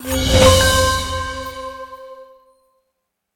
GetPoints.wav